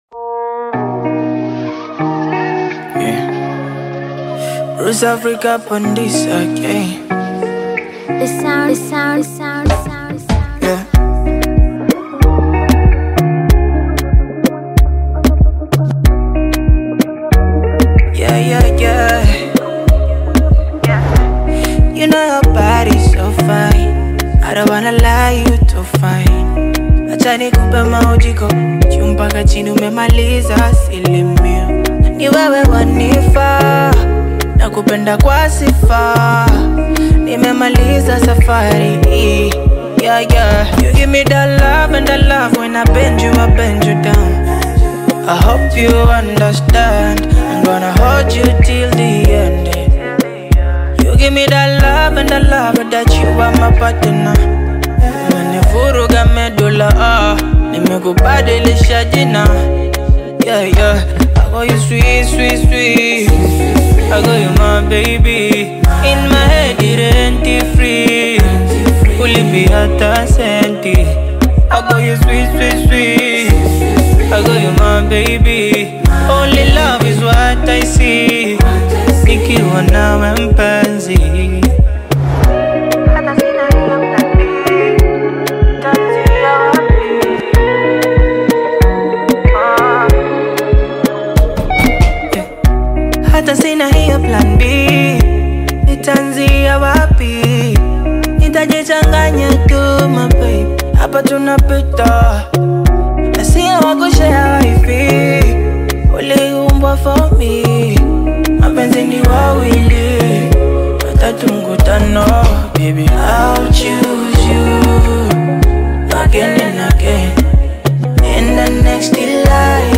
AfrobeatAudio
smooth Afro-beat single